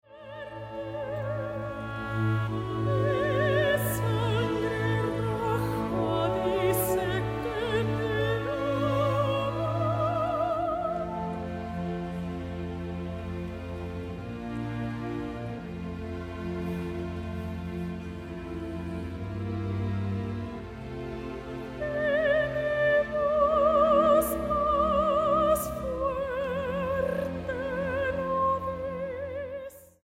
ópera en 4 actos